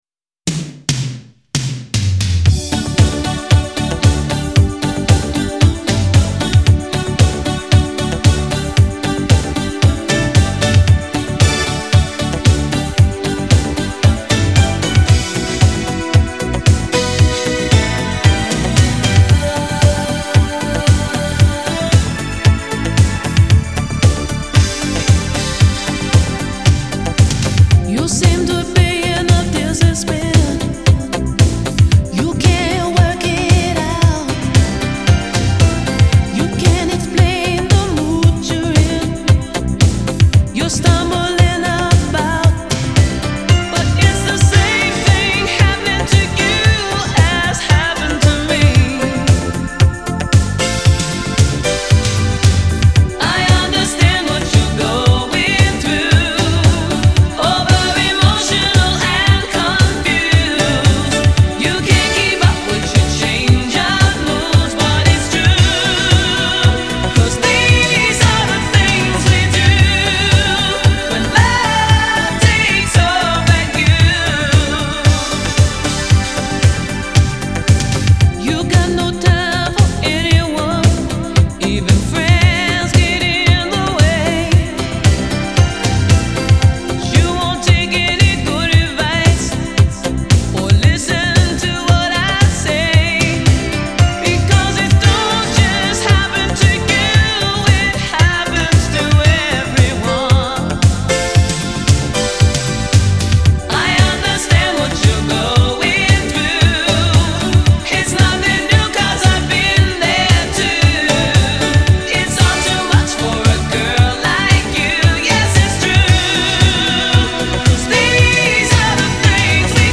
Genre : Disco